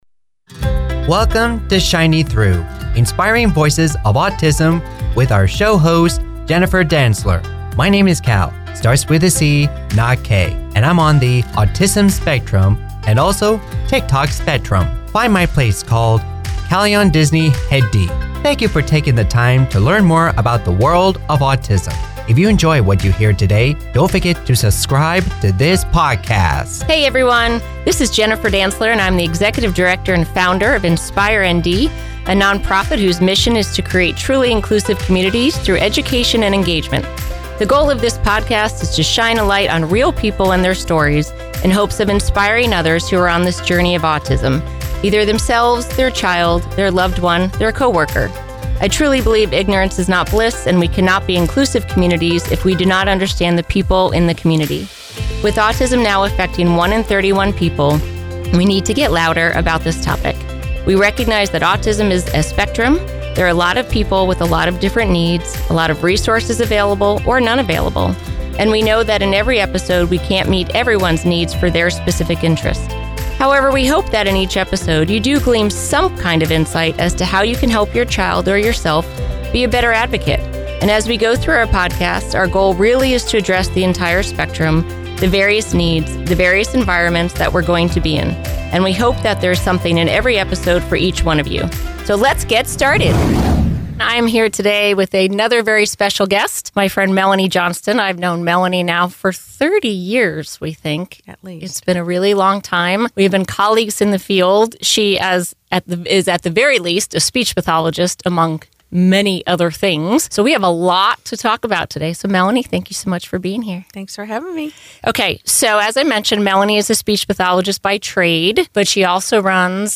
This conversation dives deep into: The difference between speech and communication Why AAC does not prevent verbal language&mdas